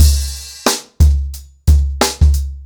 TheStakeHouse-90BPM.23.wav